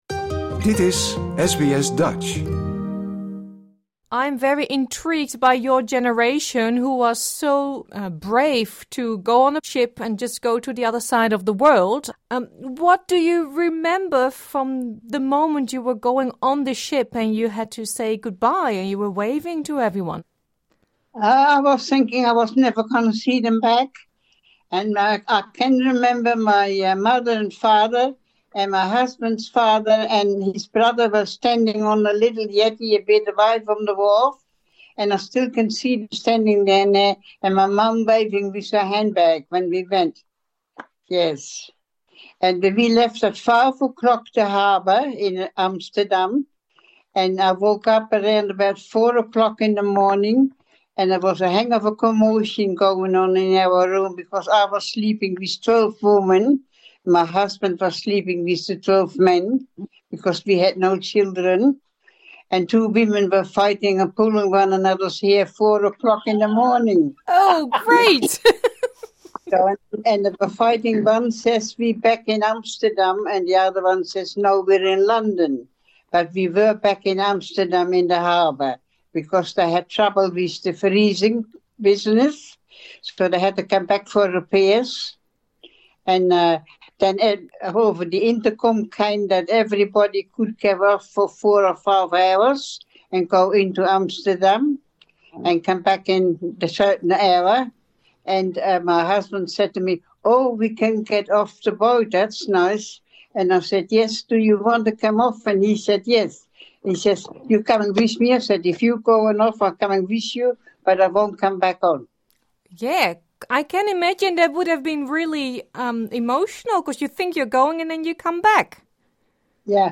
This interview is in English.